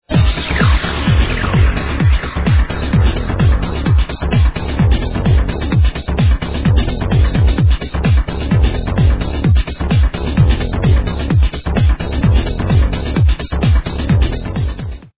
Re: Prog house track w. fat bass
Nice deep bass on this..someone's gotta know this